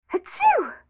sneeze.wav